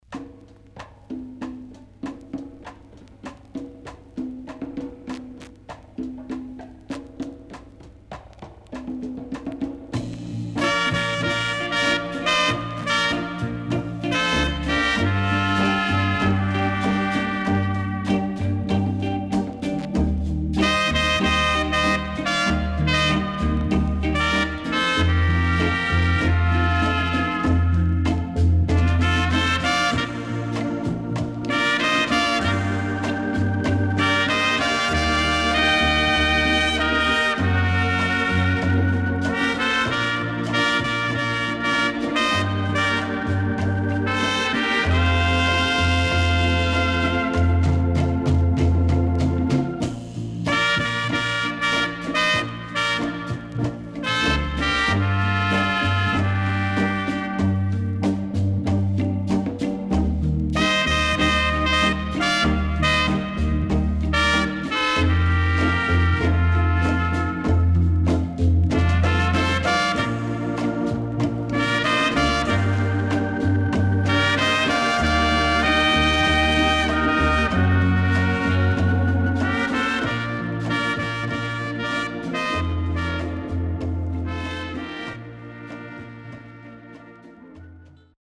Caribbean Inst LP
SIDE A:少しノイズ入りますが良好です。